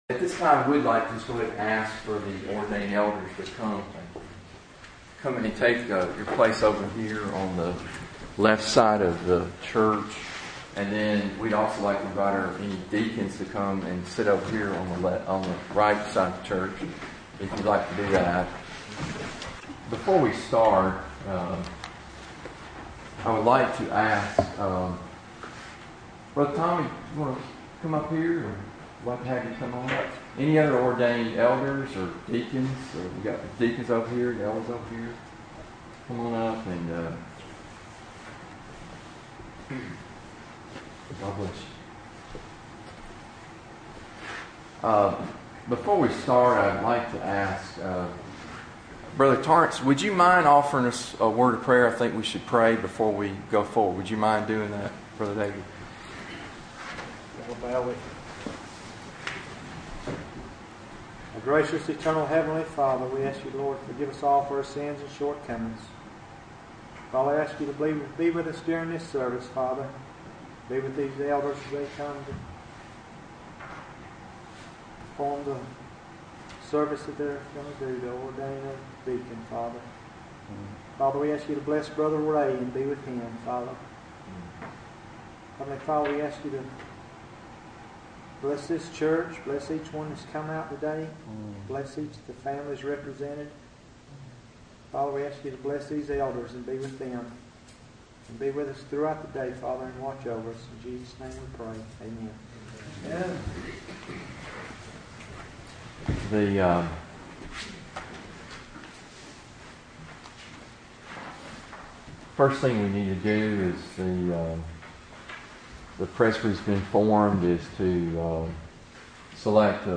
God's spirit was present and the church rejoiced in the provisions of our Lord.